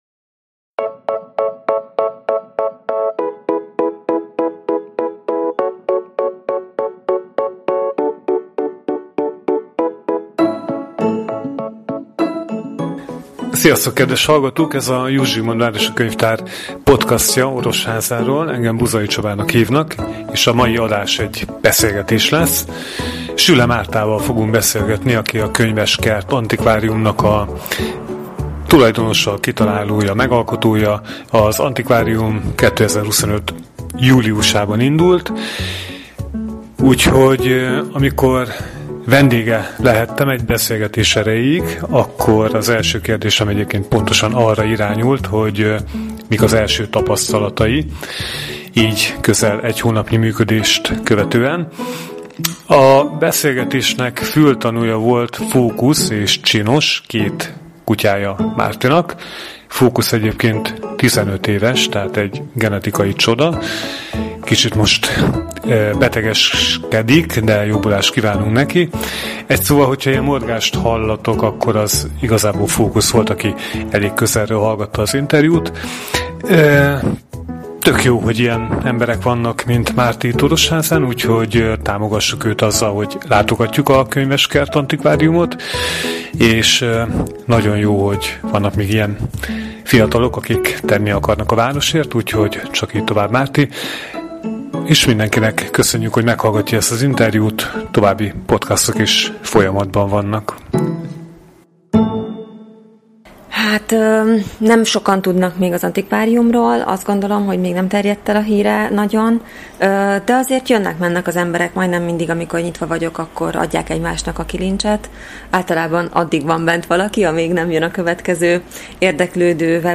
hiszen a felvétel az antikváriumban készült. A beszélgetésben szó lesz az első tapasztalatokról, az adminisztráció nehézségeiről, az álmokról és a tervekről is. Az interjúba néha belemorgott Fóki